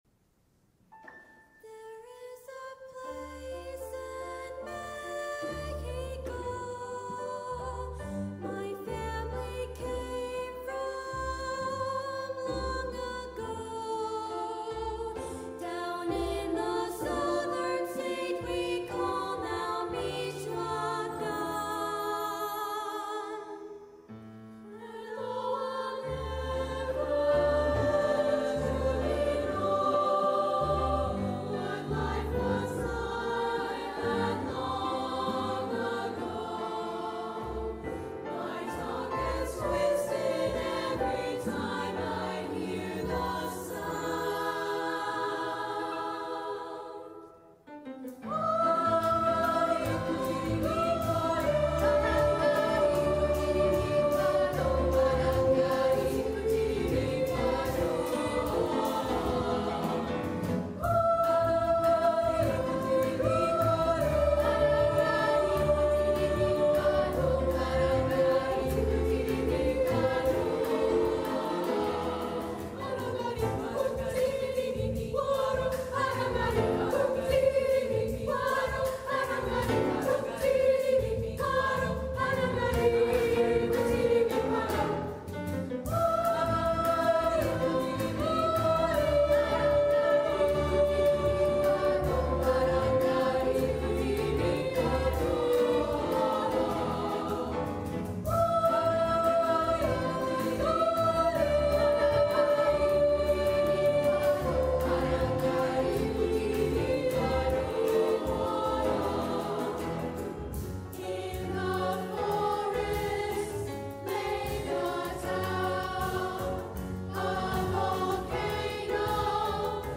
TTB + Piano (opt. Jazz Trio) 3’00”
TTB, Piano